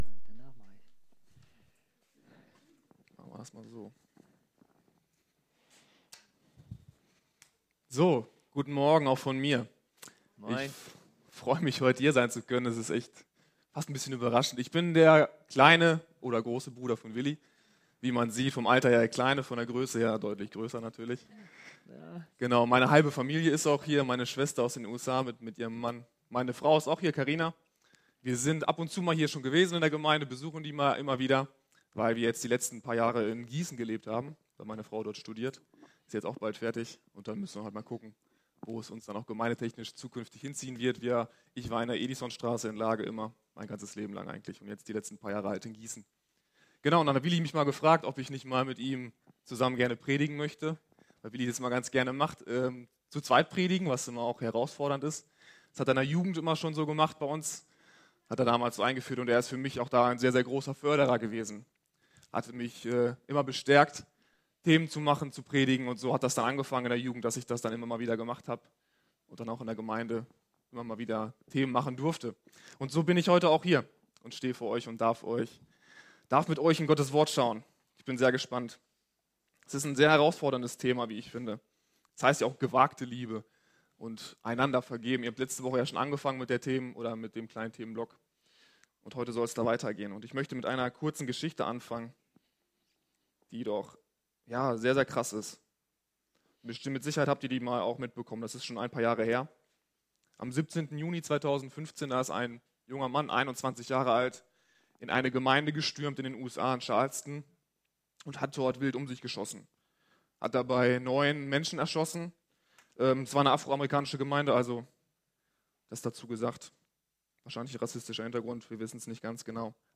Predigt vom 14. Juli 2019 – efg Lage